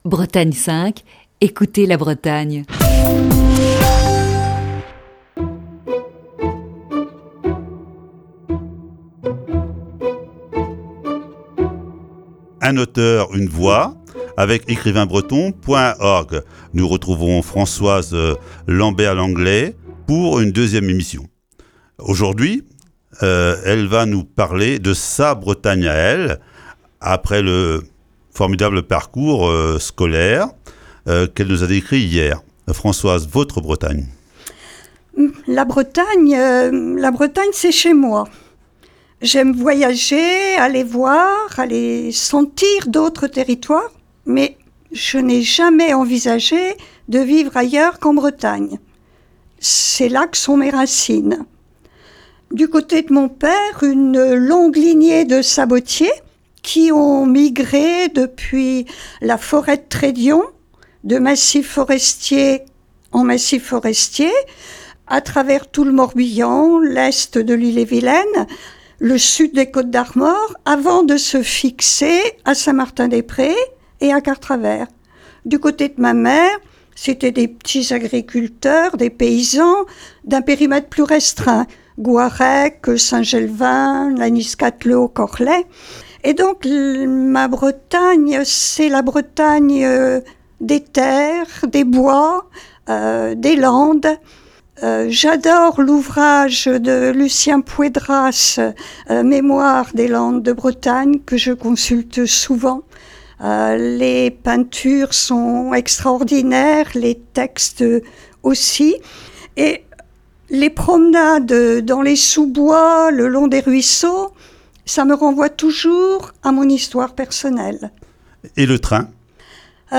(Émission diffusée le 22 octobre 2019).